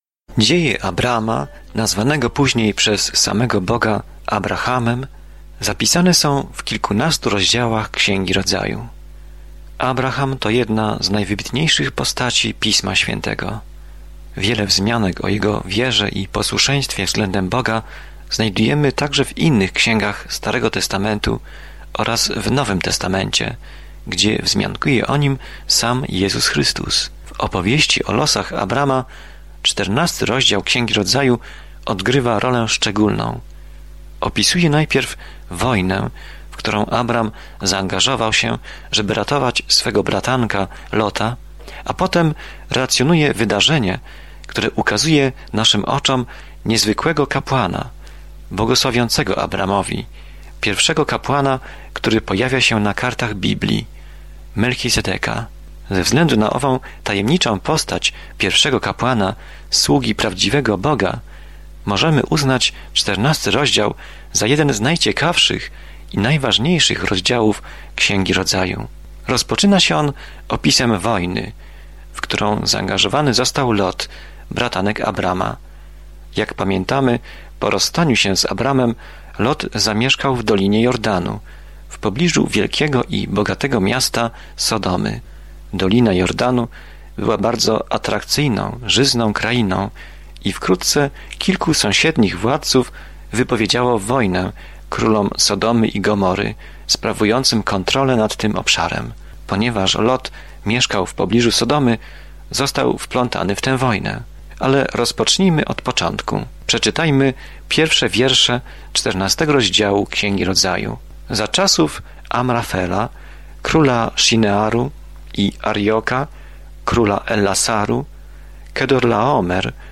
Codziennie podróżuj przez Księgę Rodzaju, słuchając studium audio i czytając wybrane wersety słowa Bożego.